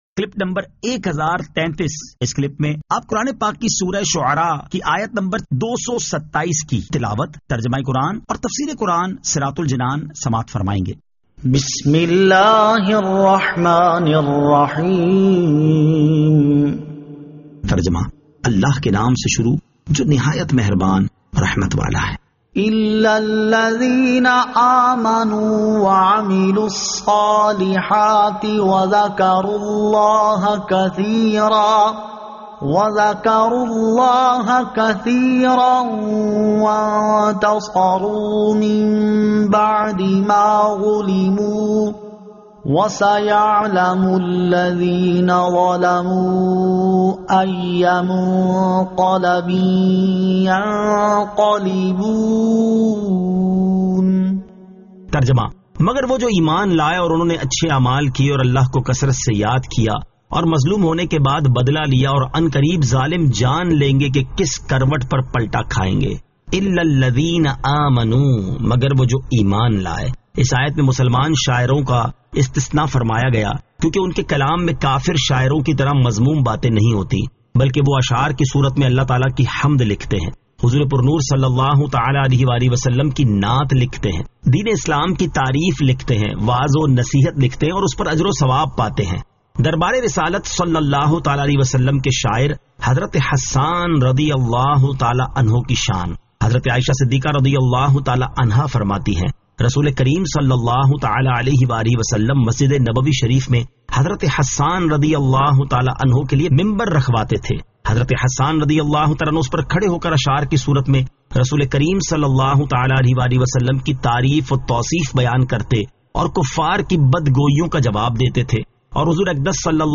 Surah Ash-Shu'ara 227 To 227 Tilawat , Tarjama , Tafseer